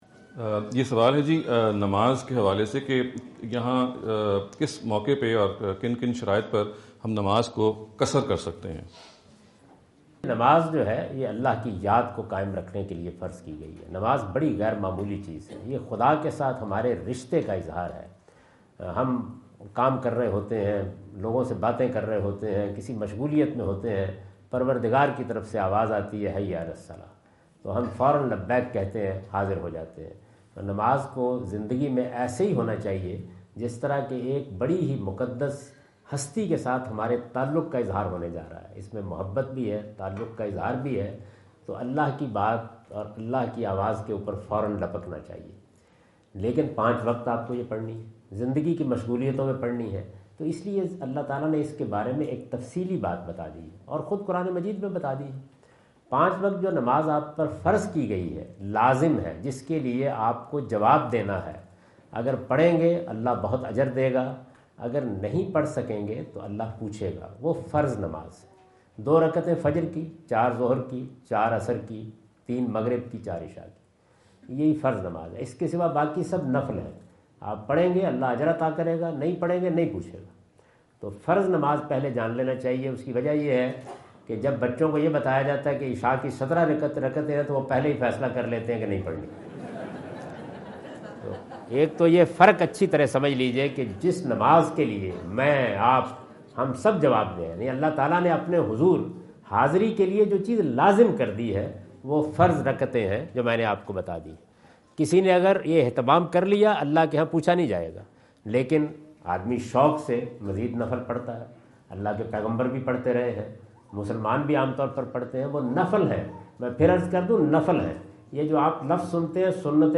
Category: English Subtitled / Questions_Answers /
Javed Ahmad Ghamidi answer the question about "Shortening the Prayer (Qasr)" asked at Corona (Los Angeles) on October 22,2017.